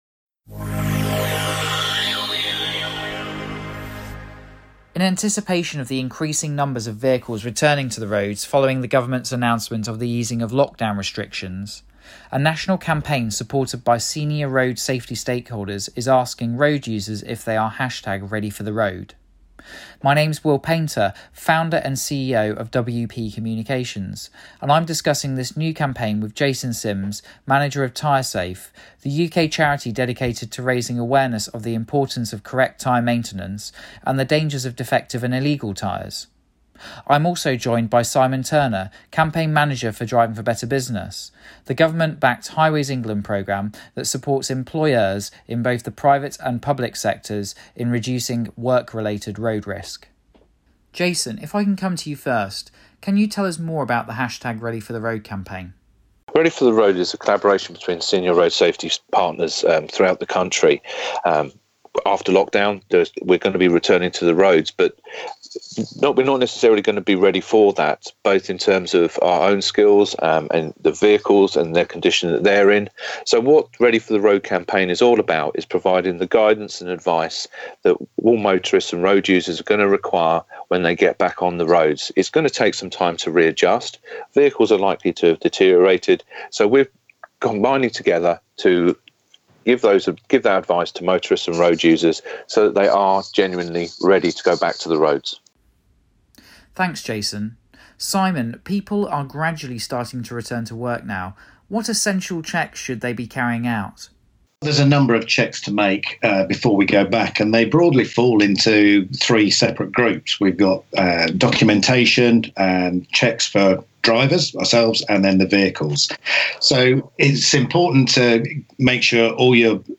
WP-Interview-TyreSafe-mixdown.mp3